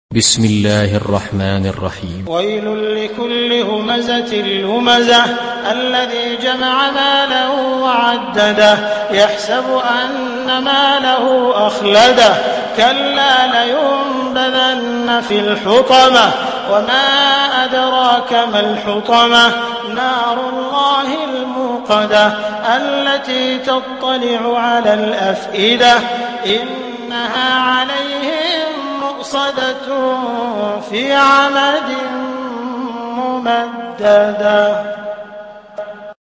دانلود سوره الهمزه mp3 عبد الرحمن السديس روایت حفص از عاصم, قرآن را دانلود کنید و گوش کن mp3 ، لینک مستقیم کامل